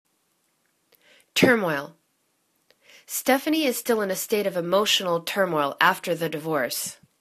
tur.moil     /turmoil/    n